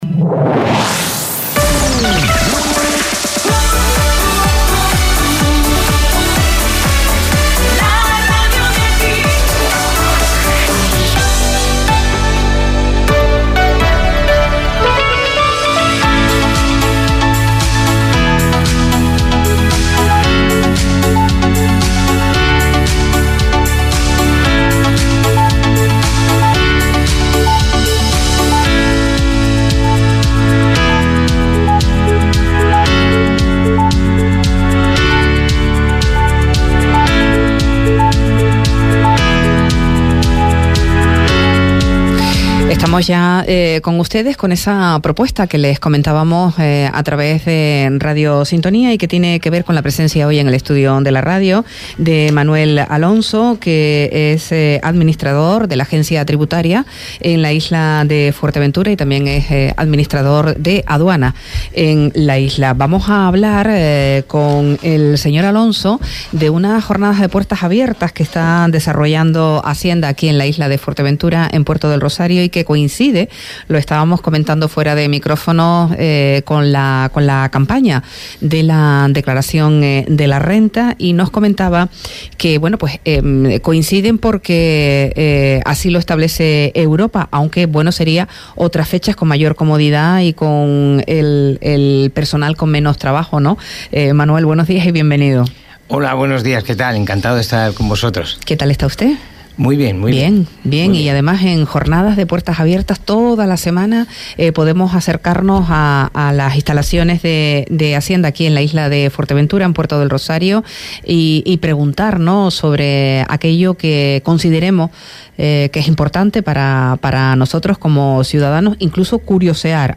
Esta mañana nos ha acompañado, en los estudios de Radio Sintonía, Manuel Alonso, director de la Delegación de Hacienda en Fuerteventura.